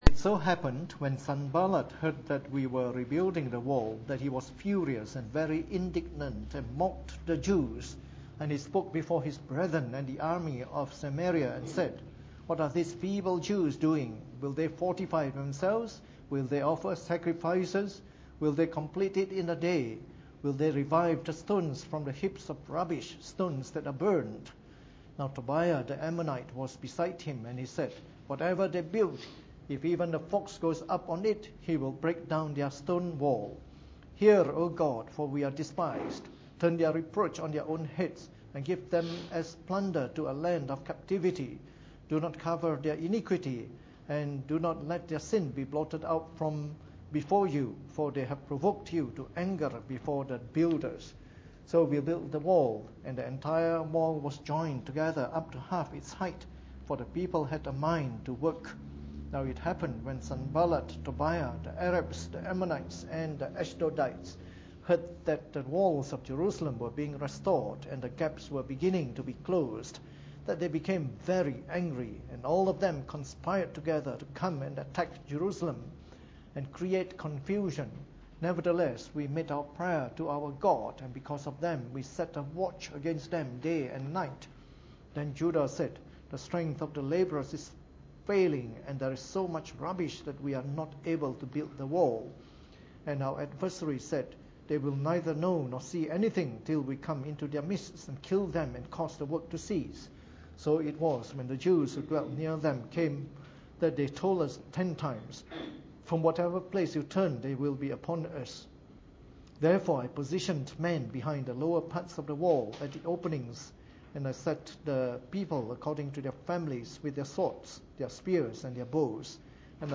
Preached on the 14th of May 2014 during the Bible Study, from our series of talks on the Book of Nehemiah.